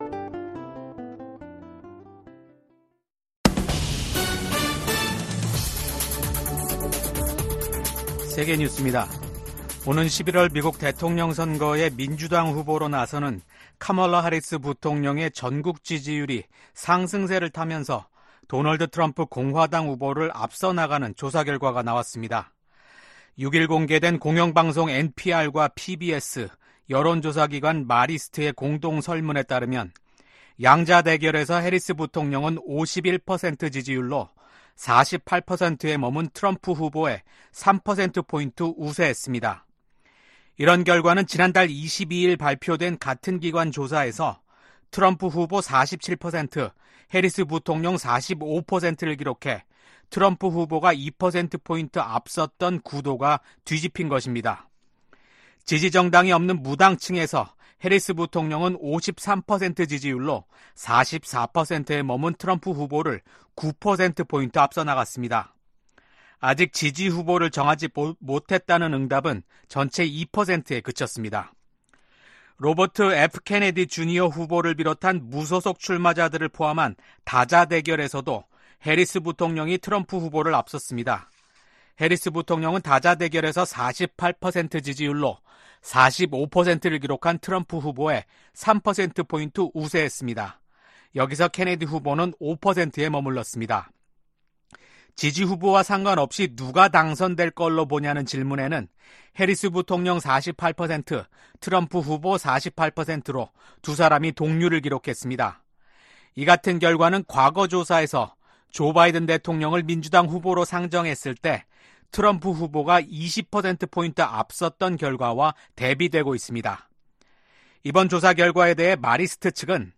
VOA 한국어 아침 뉴스 프로그램 '워싱턴 뉴스 광장' 2024년 8월 8일 방송입니다. 오는 11월 미국 대선에서 민주당 후보로 나설 예정인 카멀라 해리스 부통령이 팀 월즈 미네소타 주지사를 부통령 후보로 지명했습니다. 미국과 호주의 외교∙국방장관들이 북한과 러시아의 군사 협력을 규탄했습니다.